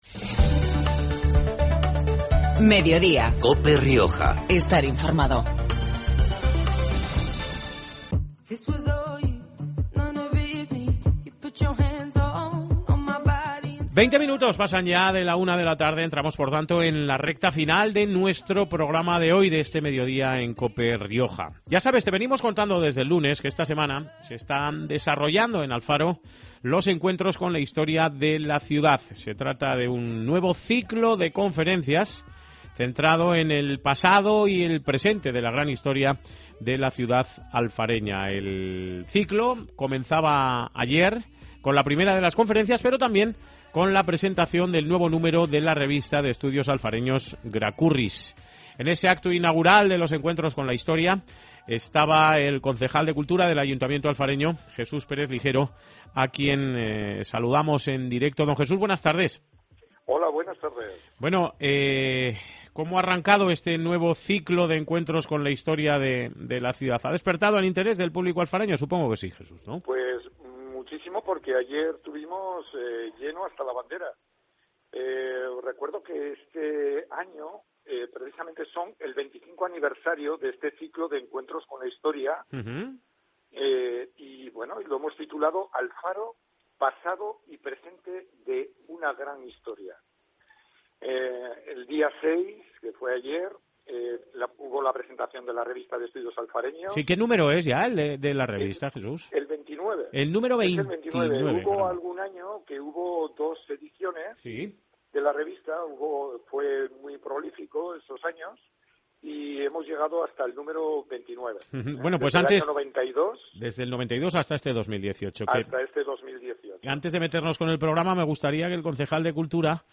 Herrera y Mediodía en Cope Rioja Baja (miércoles, 7 noviembre). Programa comarcal de actualidad, entrevistas y entretenimiento.